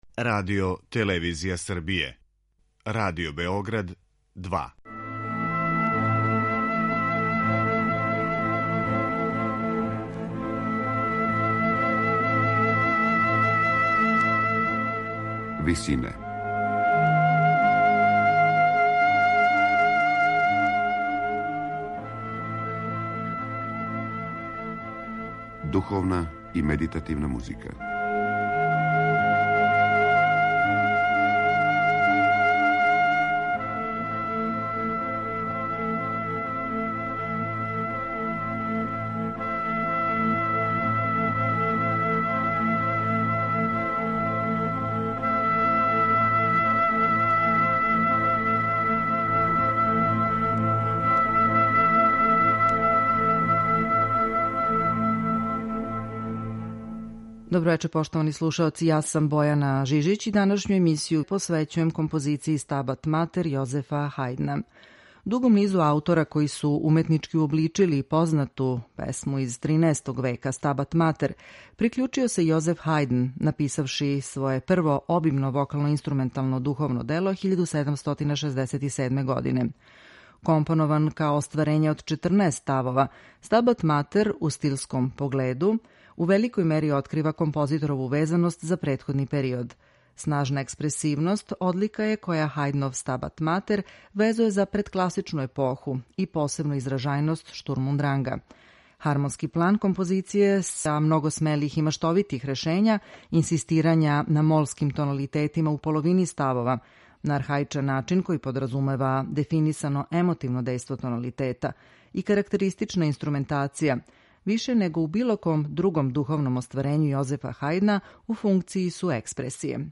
У данашњој емисији моћи ћете да слушате прво обимно вокално-инструментално дело Јозефа Хајдна, четрнаестоставачни Stabat Mater, компонован за четворо вокалних солиста, хор и оркестар.